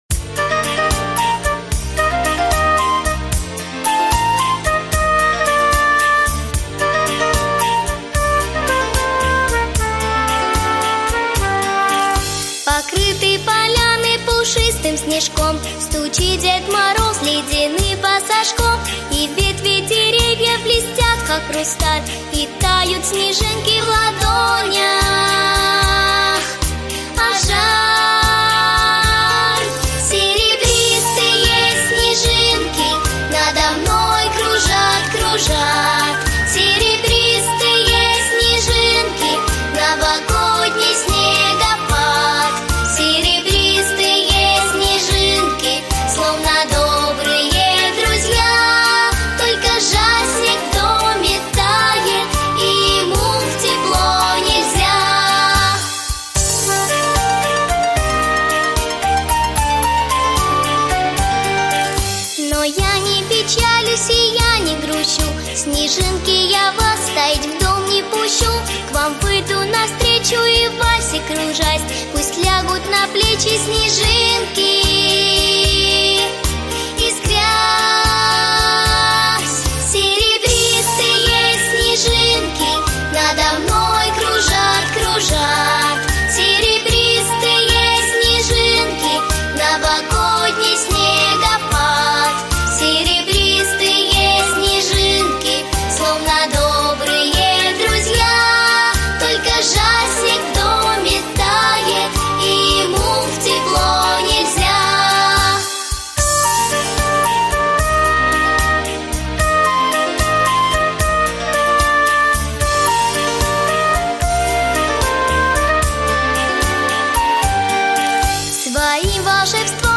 Песенки про зиму
для детского сада